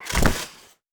Fall on Ice Intense.wav